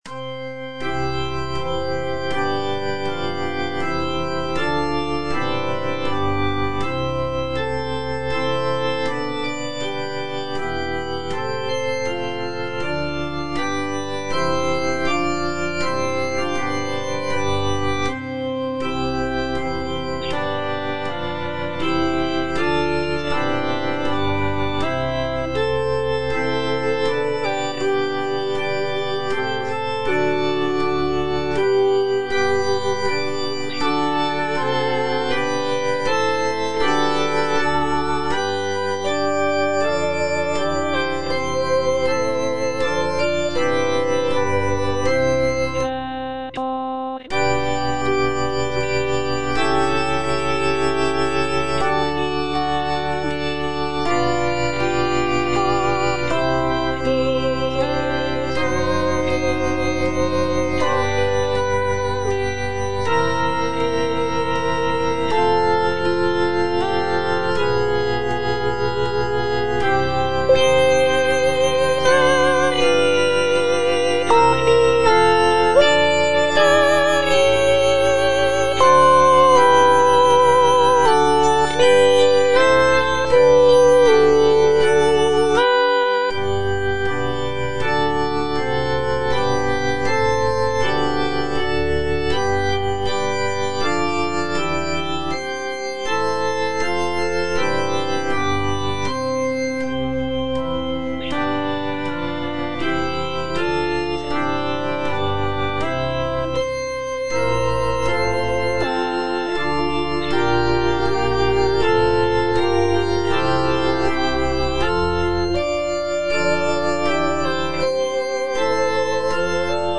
B. GALUPPI - MAGNIFICAT Suscepit Israel - Soprano (Voice with metronome) Ads stop: auto-stop